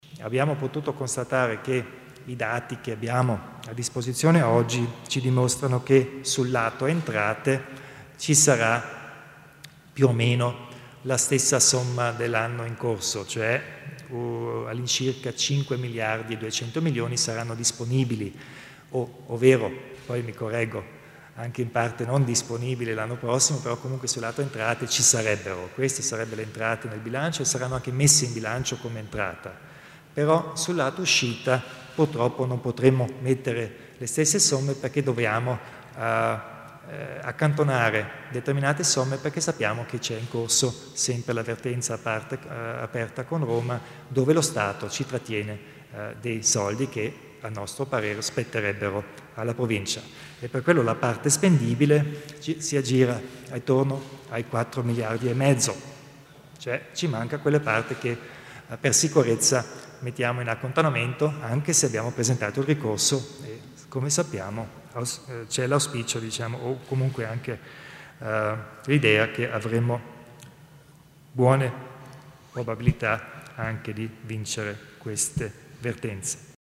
Il Presidente Kompatscher spiega il bilancio di previsione 2015